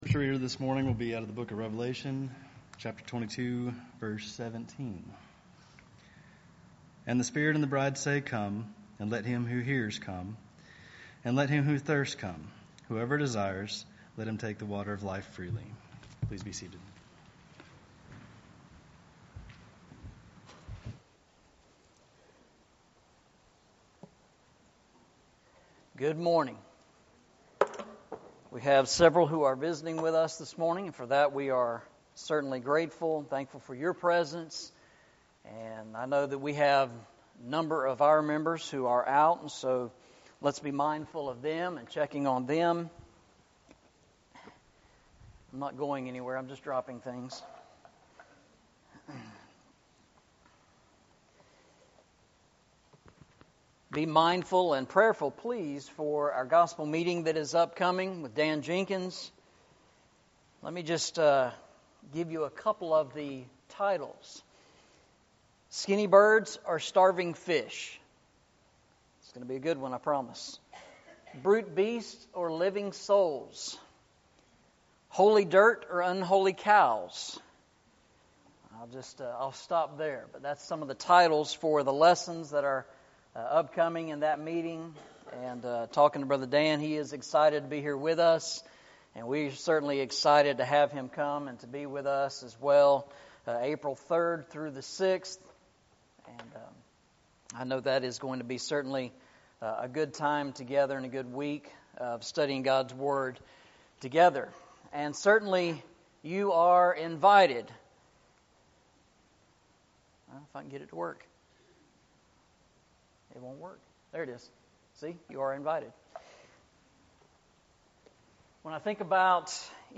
Eastside Sermons Passage: Revelation 22:17 Service Type: Sunday Morning « Walking Through the Bible